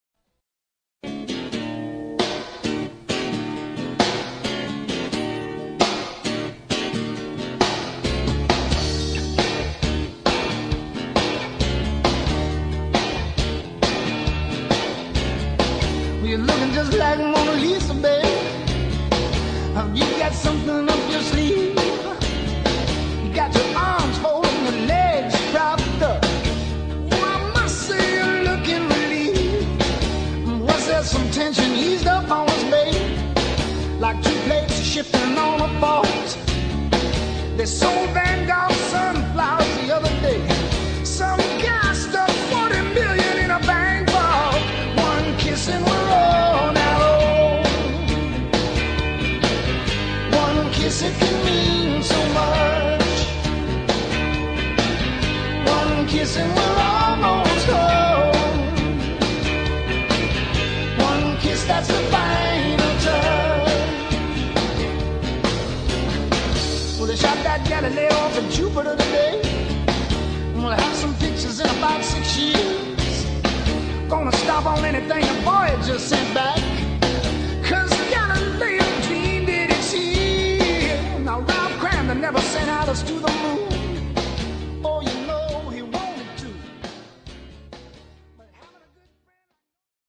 please note: demo recording
location: Nashville, date: unknown